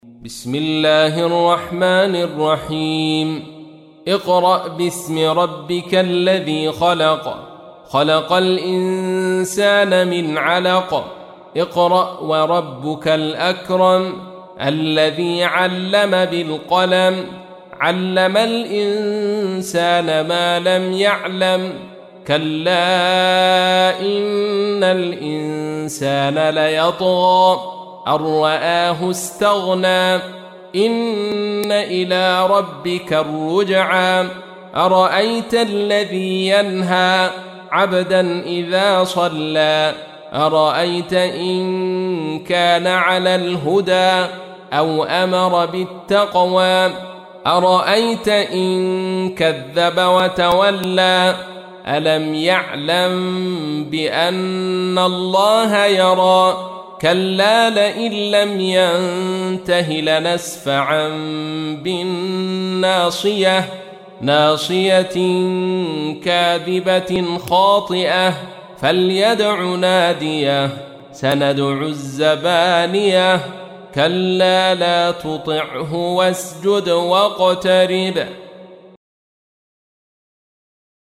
تحميل : 96. سورة العلق / القارئ عبد الرشيد صوفي / القرآن الكريم / موقع يا حسين